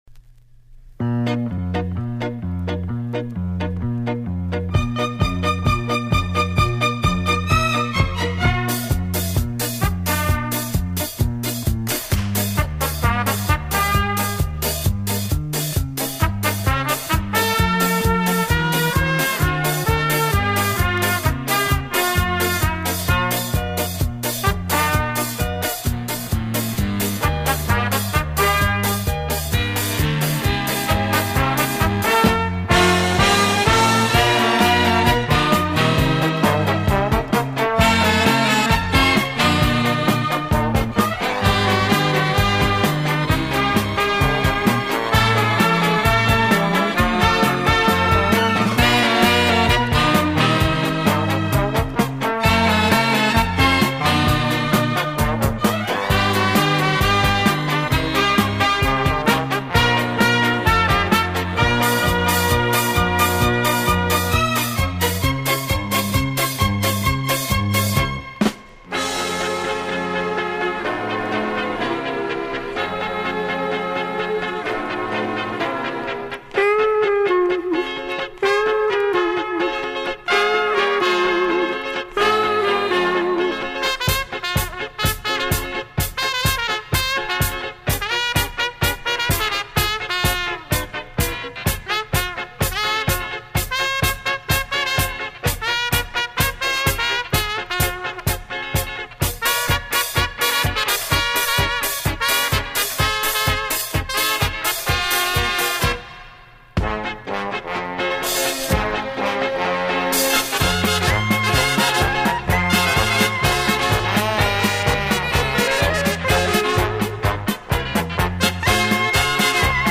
Medley 2 side B